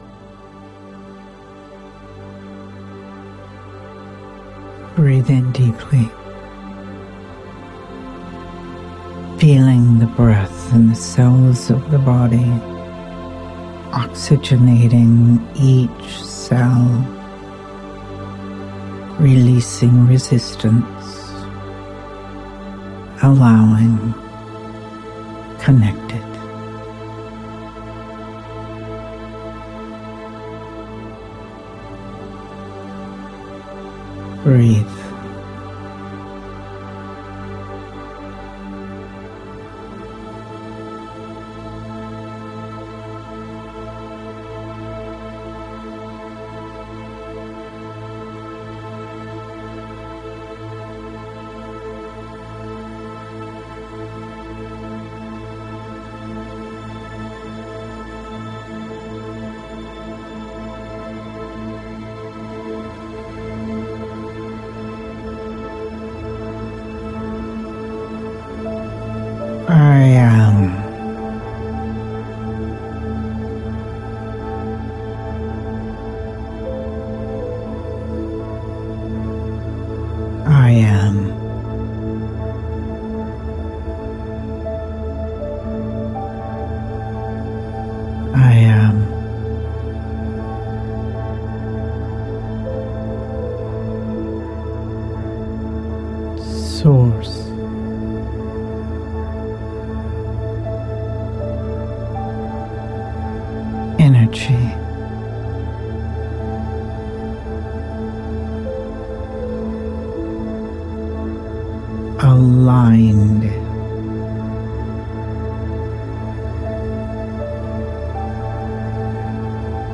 Guided Meditation 3